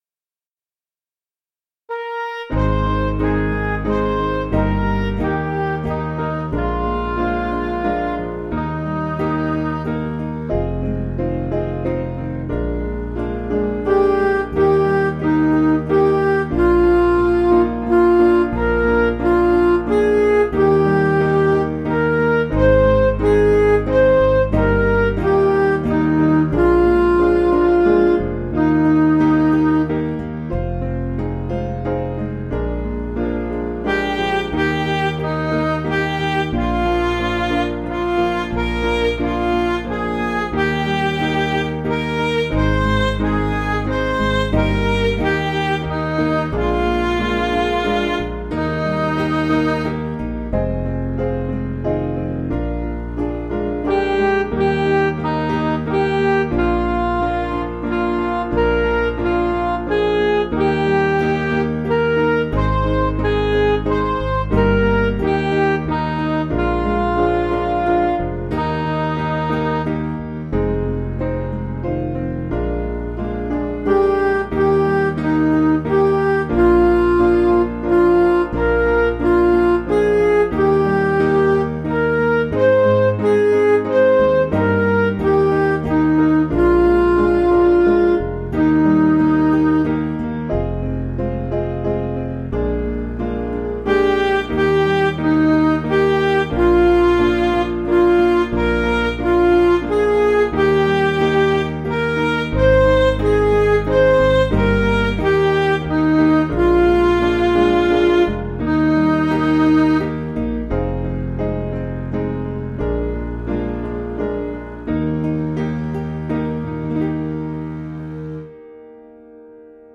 Piano & Instrumental
(CM)   5/Eb